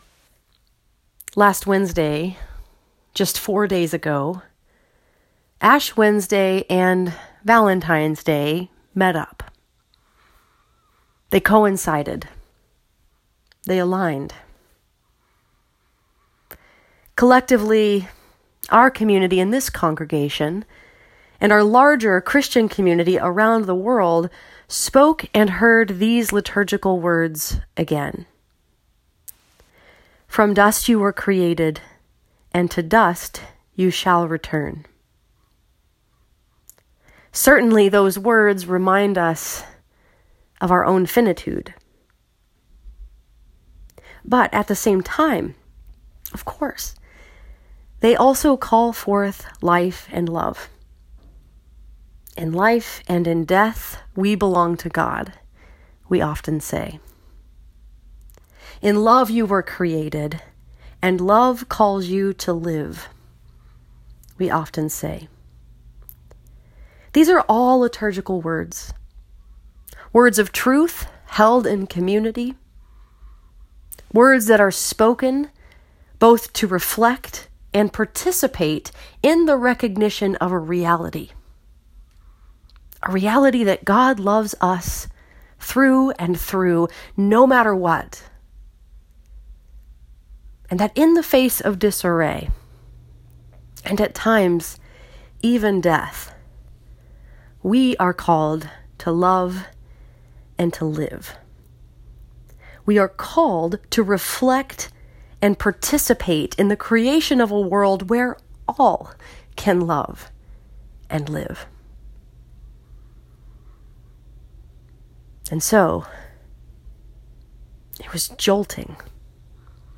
Rise Up! A Sermon Addressing Gun Violence
rise-up-a-sermon-addressing-gun-violence.m4a